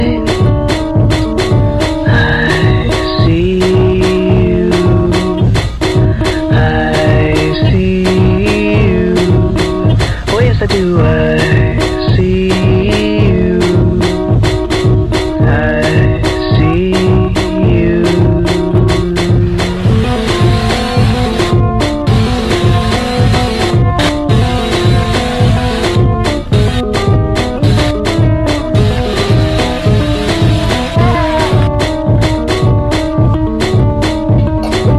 Жанр: Иностранный рок / Рок / Инди / Альтернатива